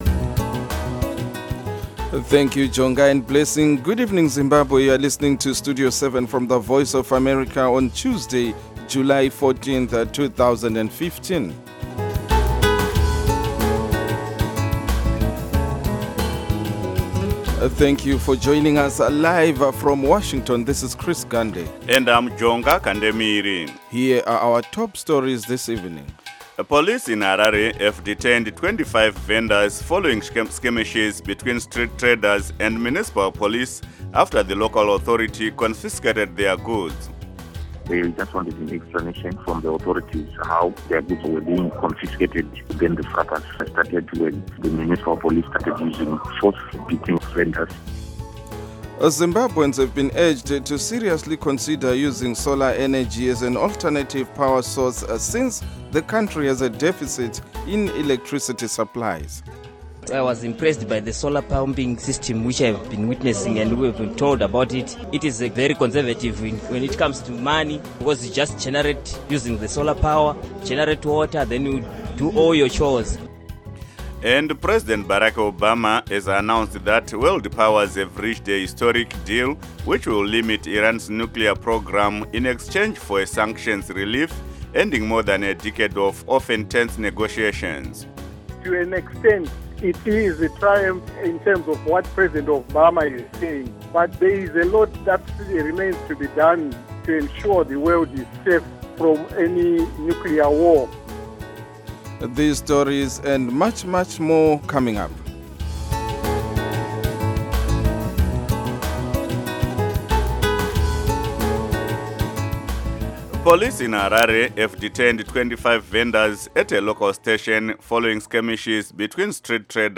Studio 7 has been providing Zimbabwe with objective, reliable and balanced radio news broadcasts since 2003 and has become a highly valued alternative point of reference on the airwaves for many thousands of Zimbabweans. Studio 7 covers politics, civil society, the economy, health, sports, music, the arts and other aspects of life in Zimbabwe.